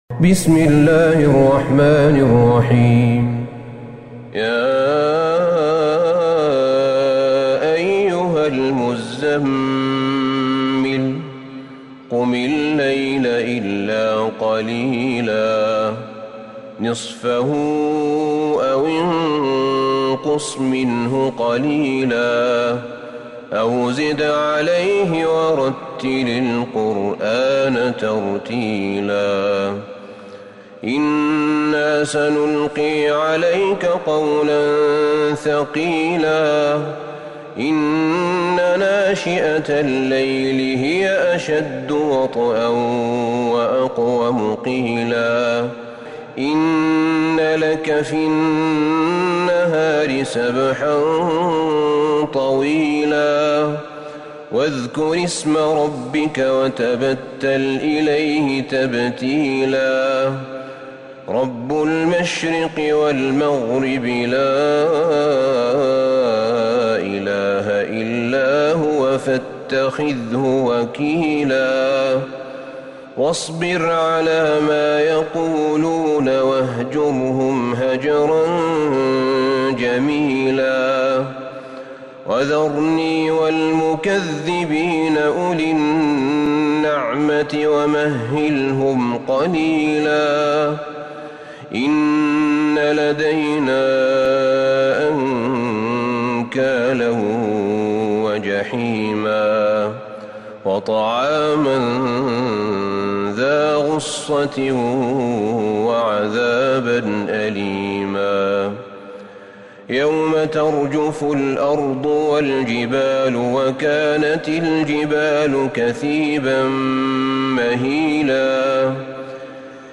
سورة المزمل Surat Al-Muzzammil > مصحف الشيخ أحمد بن طالب بن حميد من الحرم النبوي > المصحف - تلاوات الحرمين